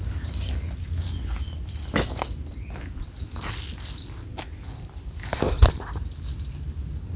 Picolezna Rojizo (Xenops rutilans)
Nombre en inglés: Streaked Xenops
Localización detallada: Misión de Loreto
Condición: Silvestre
Certeza: Fotografiada, Vocalización Grabada
Picolezna-rojizo_1.mp3